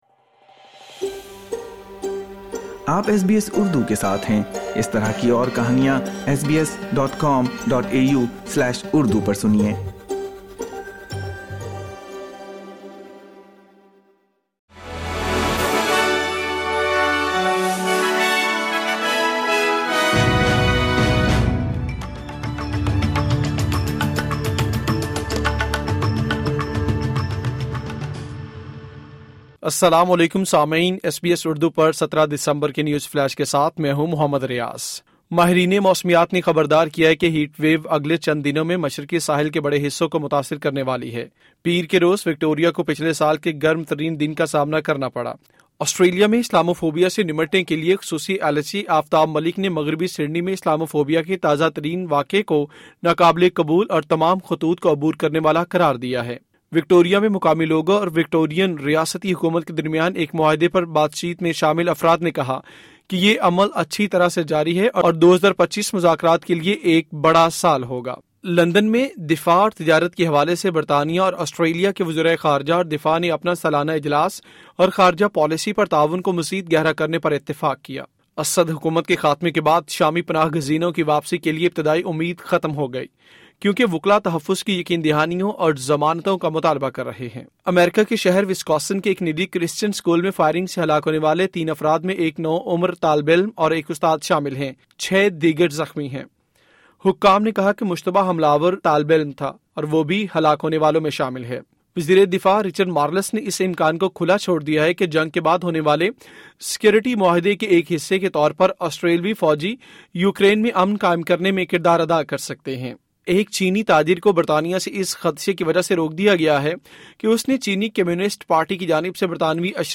اردو نیوز فلیش:17 دسمبر 2024